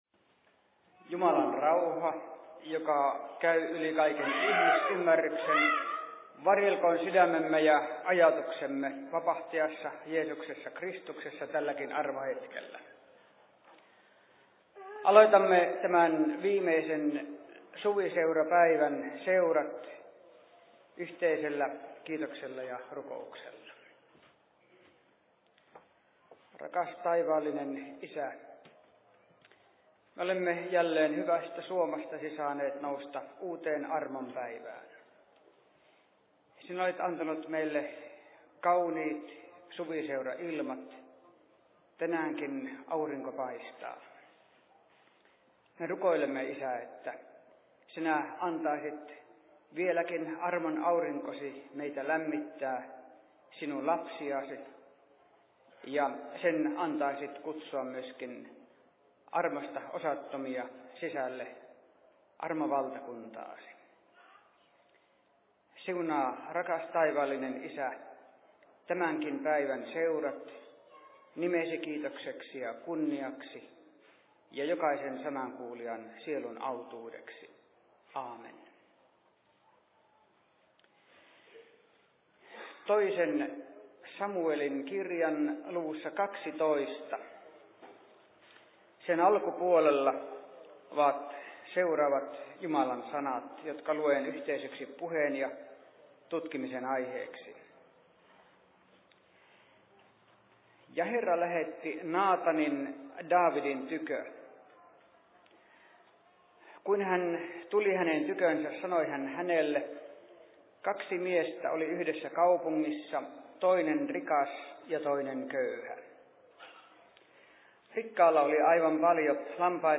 Seurapuhe 04.07.2005
Paikka: 2005 Suviseurat Perhossa